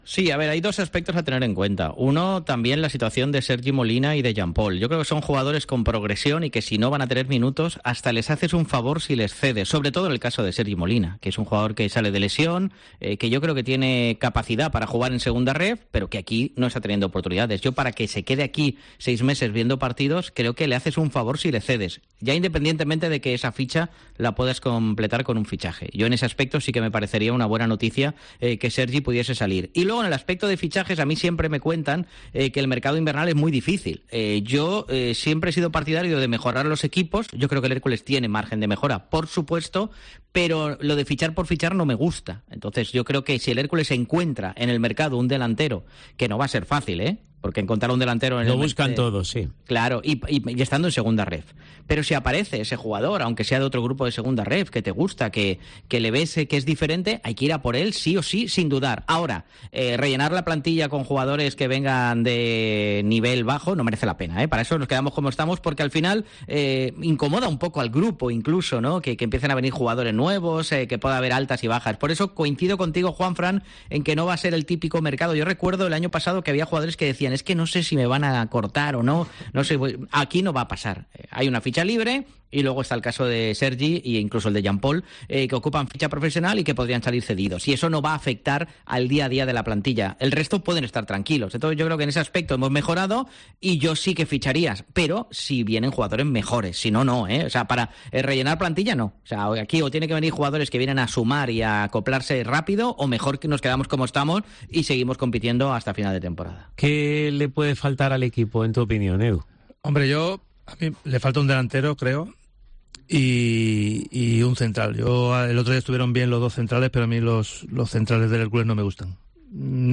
Este martes el equipo de comentaristas de COPE Alicante ha analizado en la Tertulia XXL los posibles movimientos del Hércules en la próxima ventana invernal.